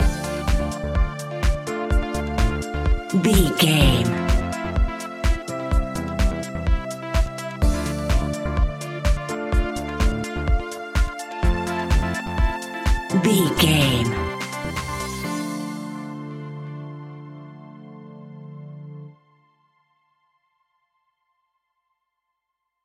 Aeolian/Minor
groovy
energetic
uplifting
hypnotic
drum machine
synthesiser
strings
funky house
deep house
nu disco
upbeat
instrumentals